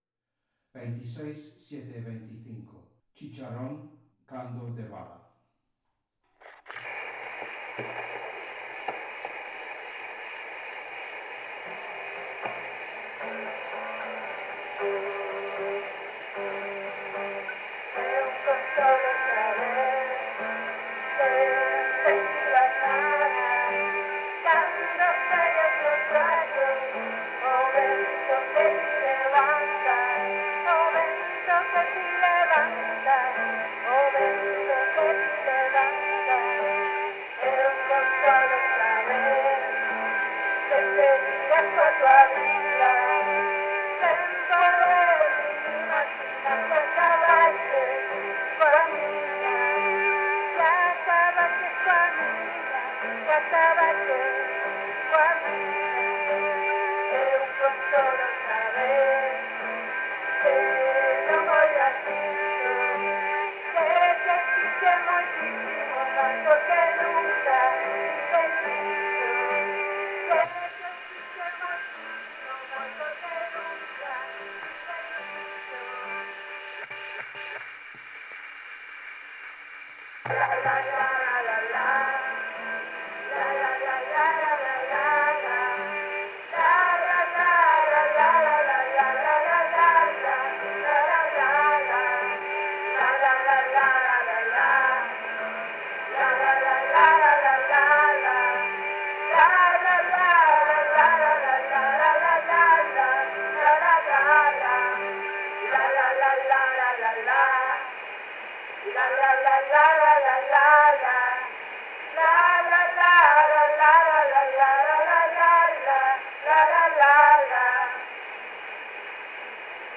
Original sound from the phonographic cylinder.
Recording place: Espazo Cafetería